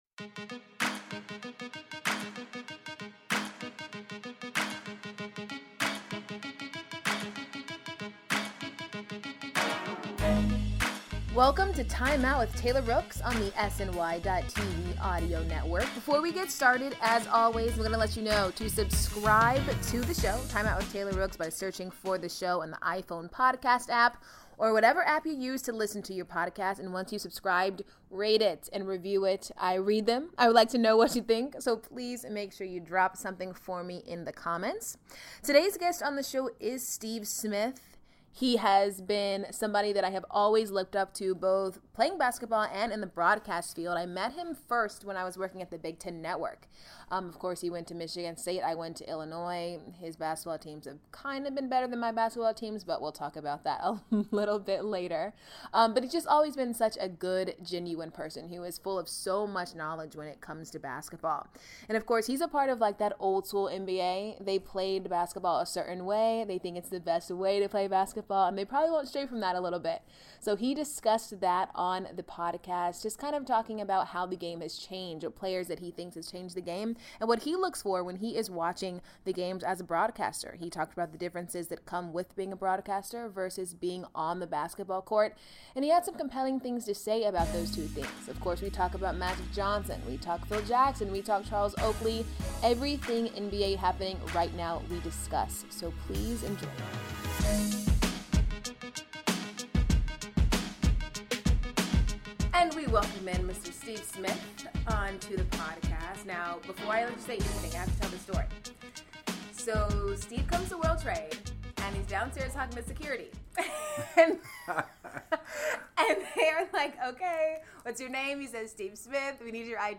On the latest edition of Timeout with Taylor Rooks, former NBA guard and NBA TV analyst Steve Smith sits down with Taylor to discuss the biggest names in the NBA, from Allen Iverson to Magic Johnson to Phil Jackson. They also get into the Charles Oakley drama from a few weeks ago and the different style of play in today's NBA as opposed to Steve's playing days with the Hawks and Spurs.